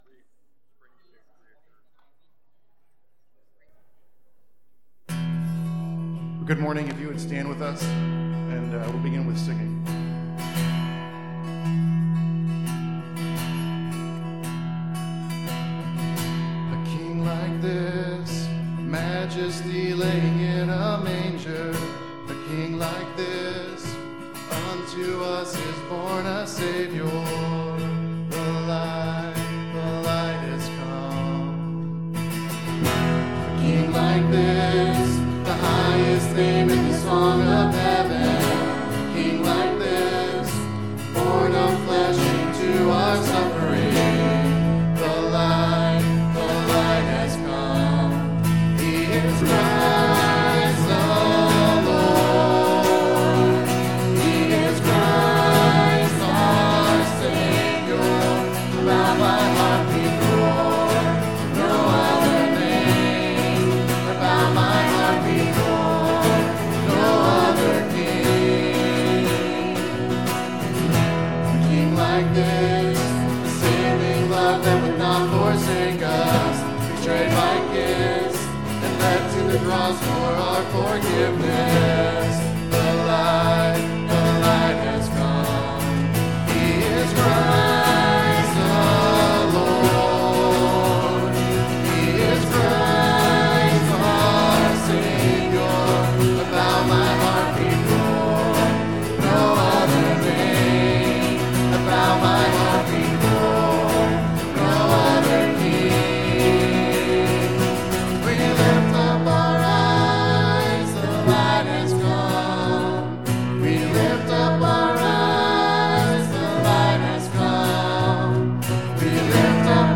Galatians 4:4 Service Type: Sunday AM Bible Text